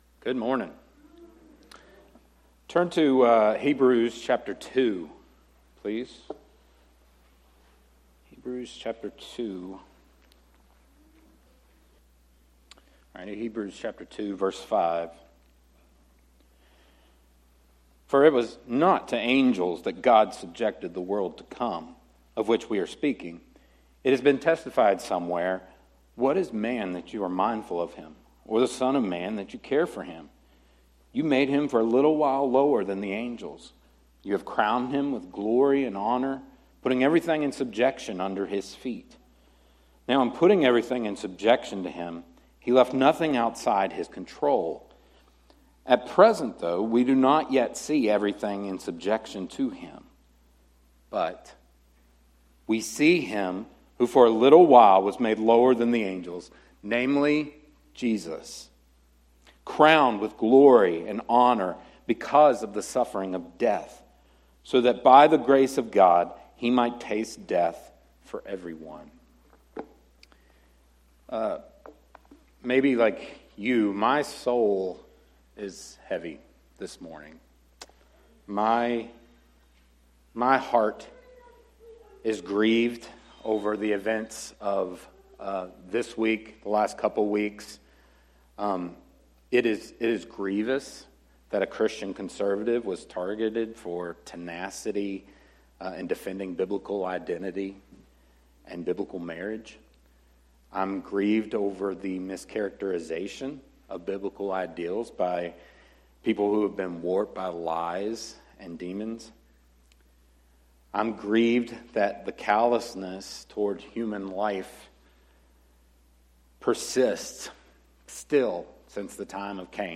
sermon-audio-trimmed-1.mp3